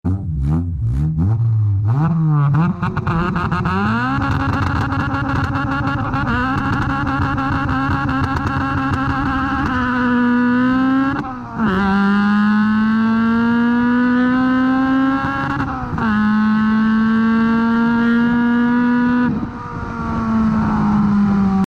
BSERIES B18 VTEC EXHAUST POV sound effects free download